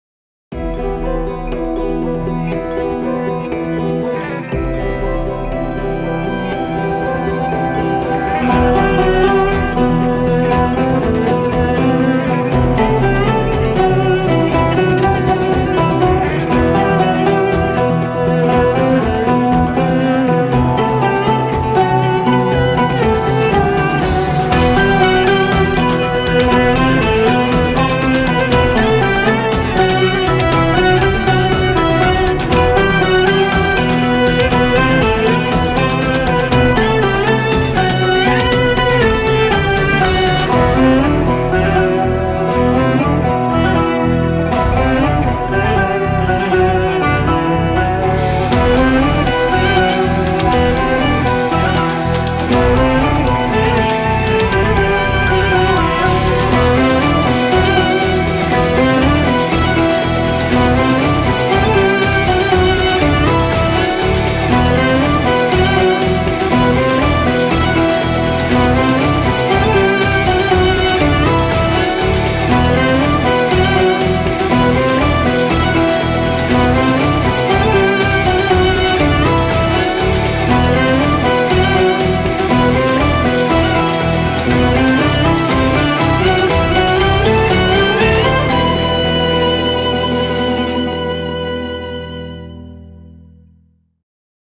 tema dizi müziği, duygusal hüzünlü enerjik fon müziği.